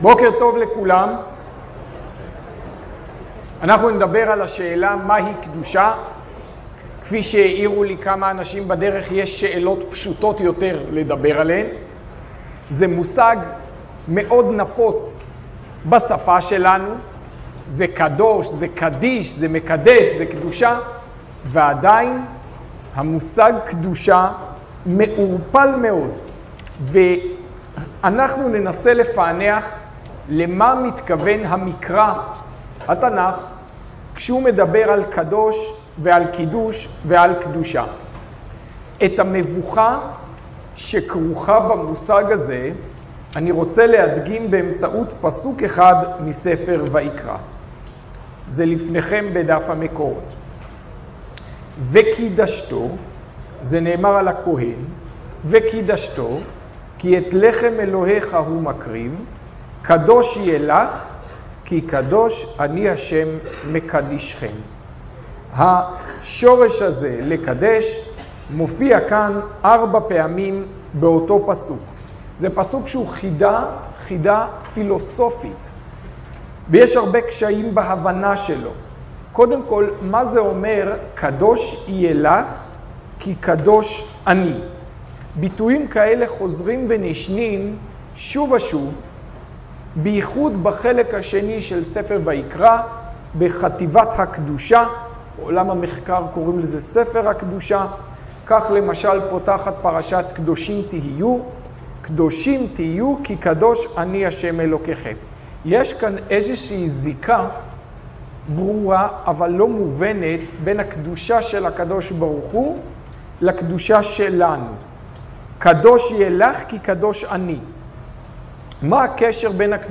השיעור באדיבות אתר התנ"ך וניתן במסגרת ימי העיון בתנ"ך של המכללה האקדמית הרצוג תשע"ח